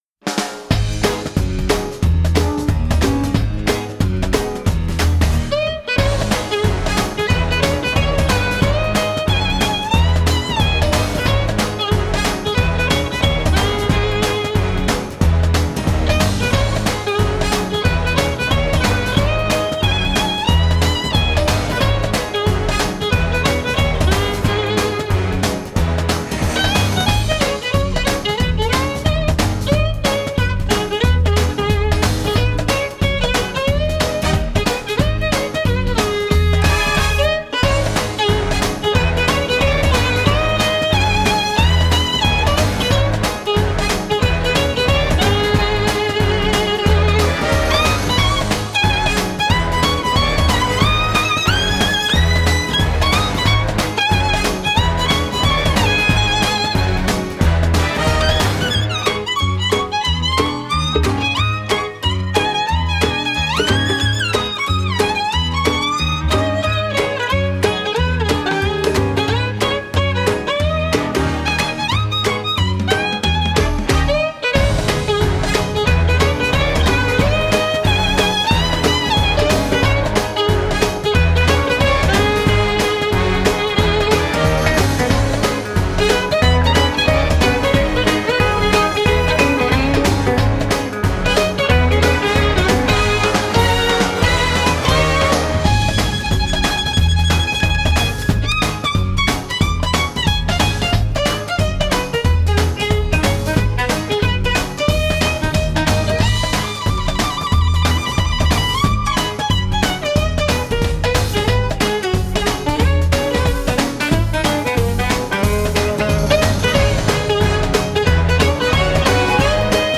Genre: Score